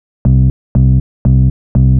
TSNRG2 Off Bass 014.wav